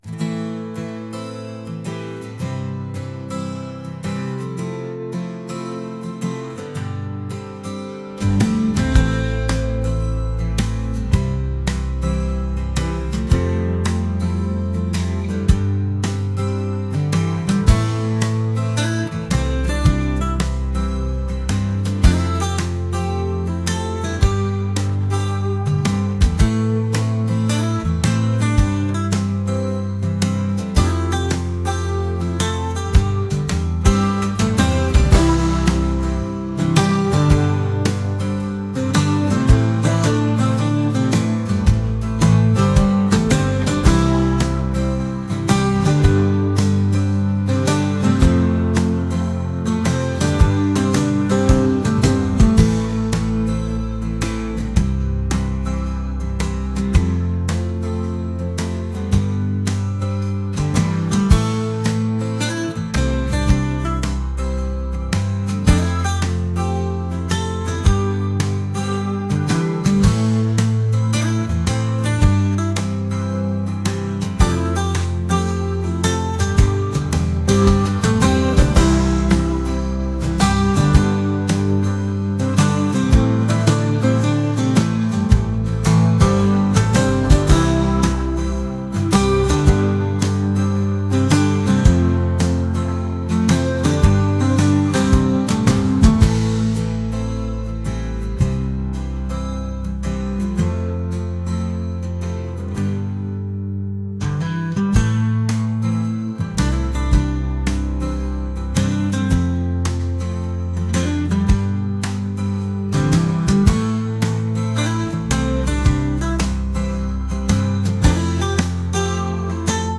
acoustic | pop | laid-back